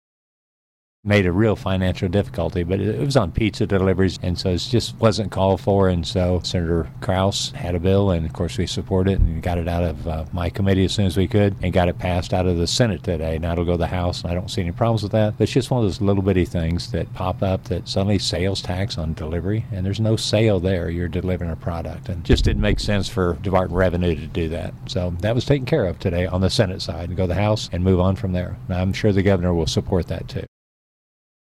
1. Senator Cunningham says Missouri senators have given approval to Senate Bill 16, legislation that seeks to exempt delivery charges from sales and use taxes.